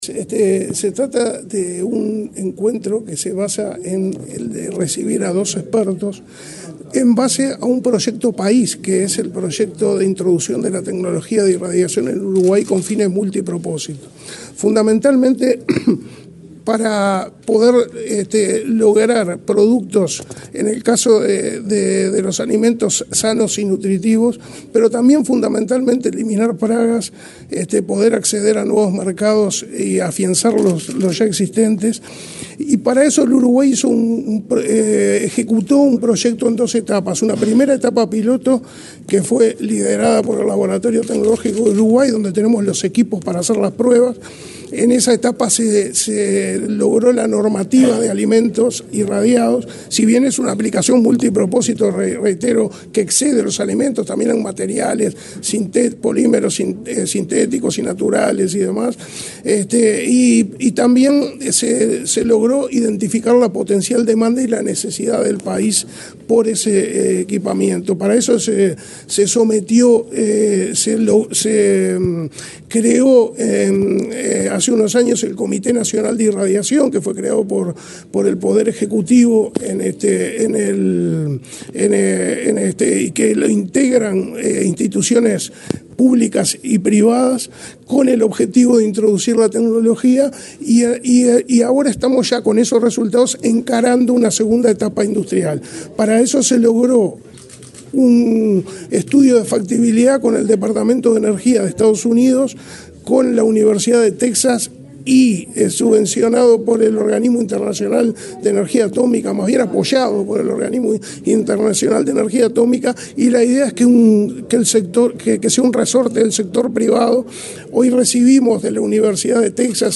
Entrevista al director nacional de Aplicaciones de Energía Nuclear del MIEM, Aníbal Abreu
El director nacional de Aplicaciones de Energía Nuclear del Ministerio de Industria, Energía y Minería (MIEM), Aníbal Abreu, dialogó con Comunicación